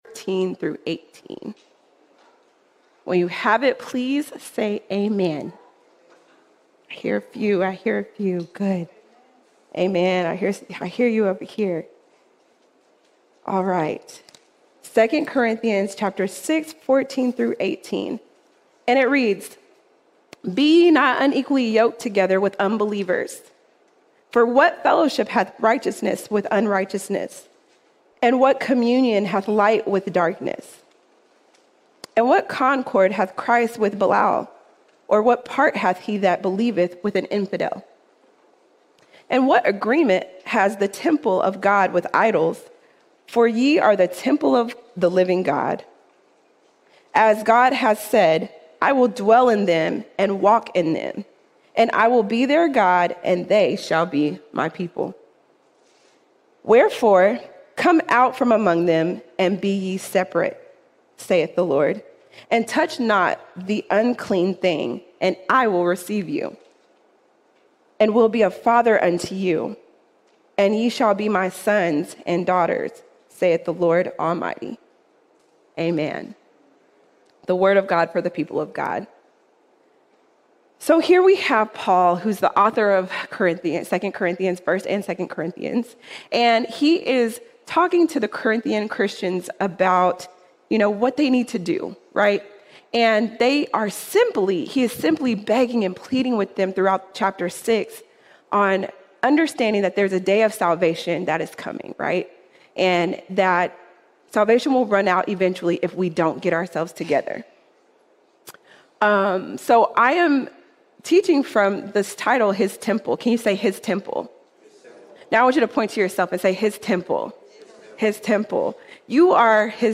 29 September 2025 Series: Sunday Sermons All Sermons His Temple His Temple Because of what Jesus did on the cross, not only are we saved, we are now the temple of the living God.